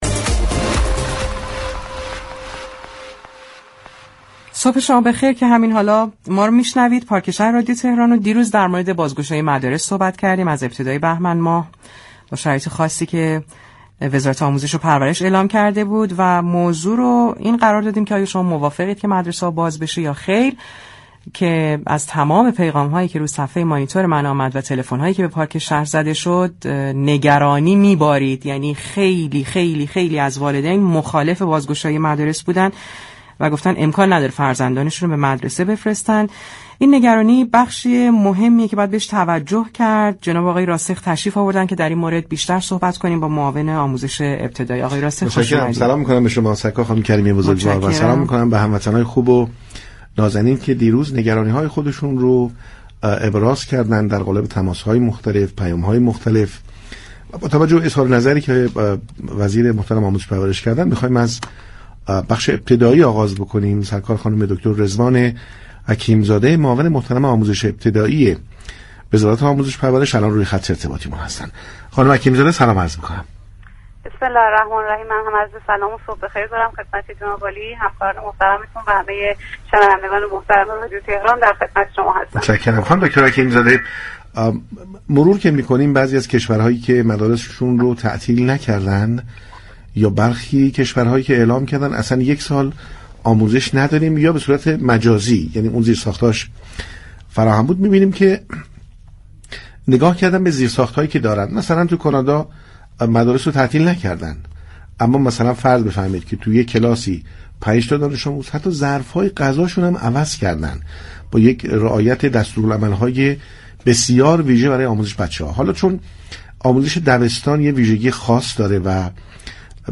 در همین راستا برنامه پارك شهر با رضوان حكیم زاده معاون آموزش ابتدایی وزارت آموزش و پرورش گفتگو كرد.